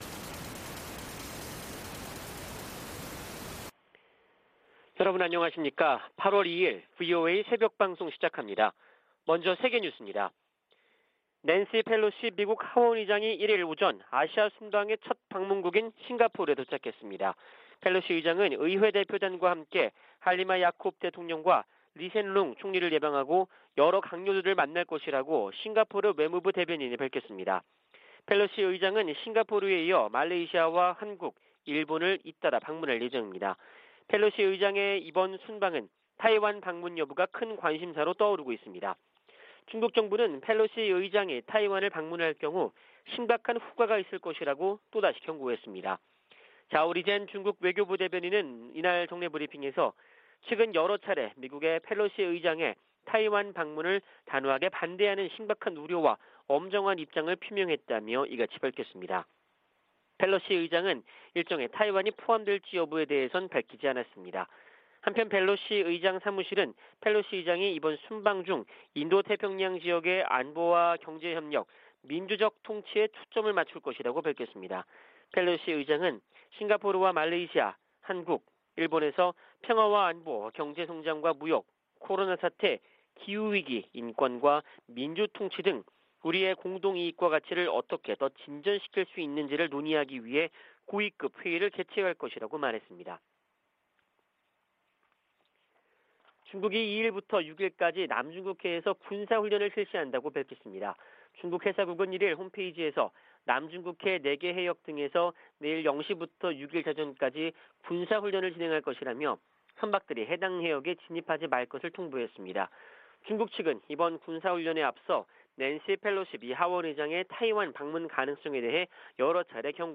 VOA 한국어 '출발 뉴스 쇼', 2022년 8월 2일 방송입니다. 백악관 국가안보회의(NSC) 고위관리가 미-한 연합훈련과 관련해 준비태세의 중요성을 강조하고, 한반도 상황에 맞게 훈련을 조정하고 있다고 밝혔습니다. 미 국방부는 중국의 사드 3불 유지 요구와 관련해 한국에 대한 사드 배치는 두 나라의 합의에 따라 결정될 것이라는 입장을 밝혔습니다. 밥 메넨데즈 미 상원 외교위원장이 '쿼드'에 한국을 포함해야 한다고 말했습니다.